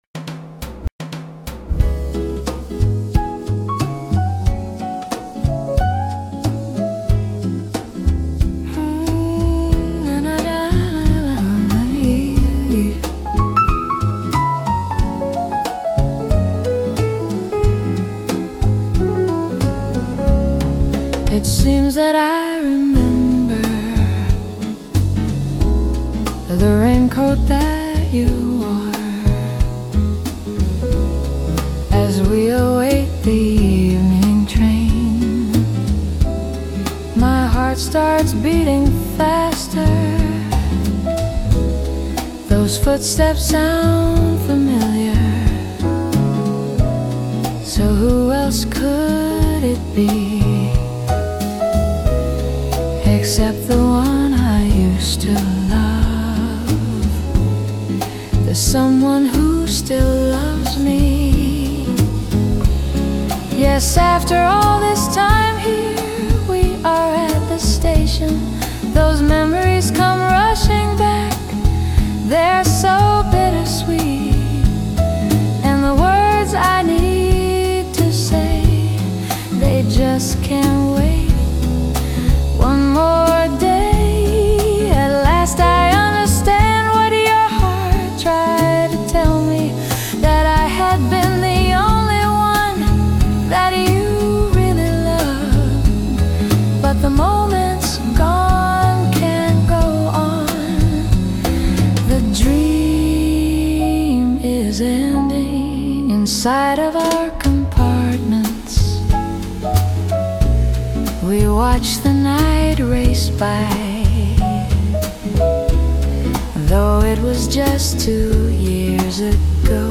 Jazz Vocal Version)｜切なく響くジャズアレンジ
しっとりとした大人のジャズボーカル風にアレンジしました。
音声または映像は、著しく編集されたもの、またはデジタルにより生成されたものです